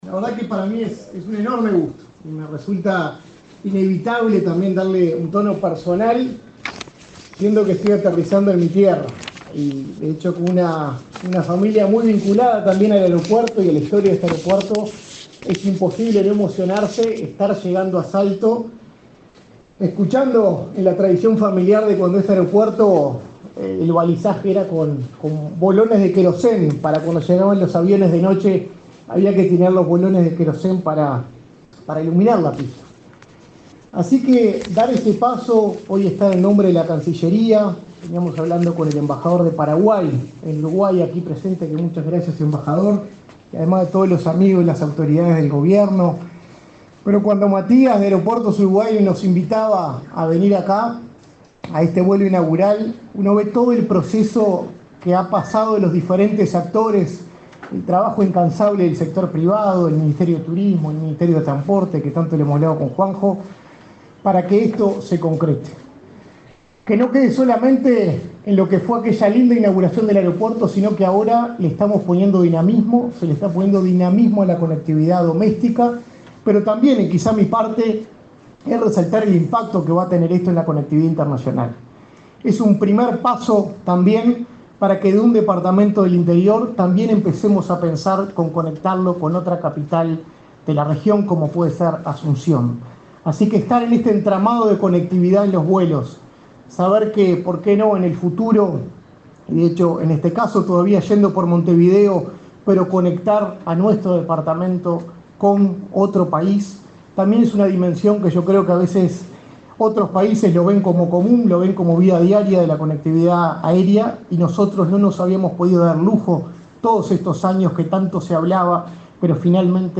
Palabras del subsecretario de Relaciones Exteriores, Nicolás Albertoni
Palabras del subsecretario de Relaciones Exteriores, Nicolás Albertoni 08/10/2024 Compartir Facebook X Copiar enlace WhatsApp LinkedIn Este martes 8, el subsecretario de Relaciones Exteriores, Nicolás Albertoni, participó en el acto de recepción del vuelo inaugural de la nueva ruta de la aerolínea Paranair que conectará Montevideo con Salto.